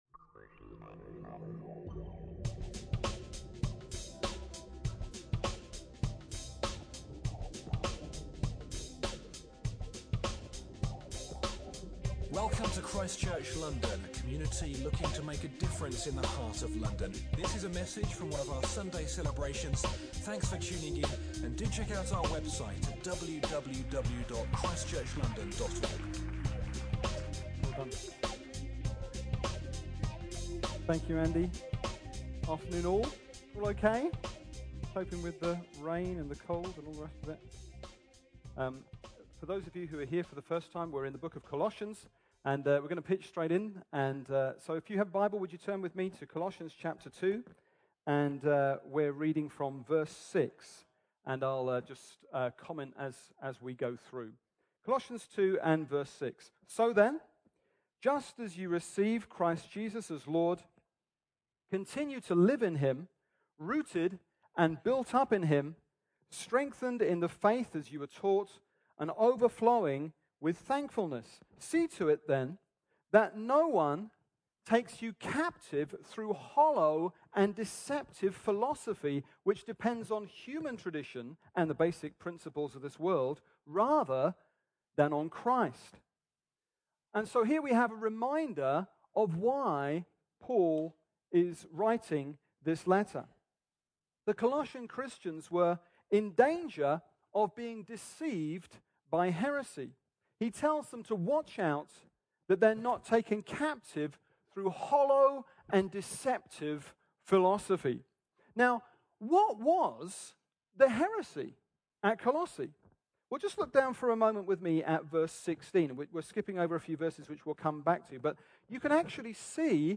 Colossians 2 – Preaching from ChristChurch London’s Sunday Service